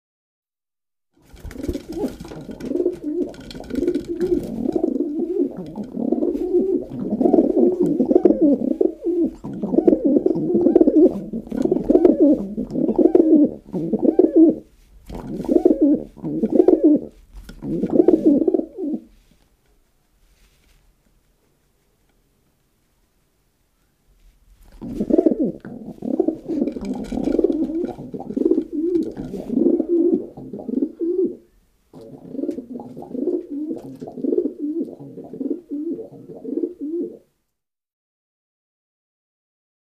Dove, Rock Coo. A Series Of Warbling Pigeon Coos From Several Birds At Once. Close Perspective. Pigion